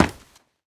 scpcb-godot/SFX/Step/Run3.ogg at 34e20b9e84c4340c5663a408ff2cef388eb0407a
Run3.ogg